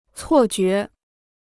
错觉 (cuò jué): misconception; illusion.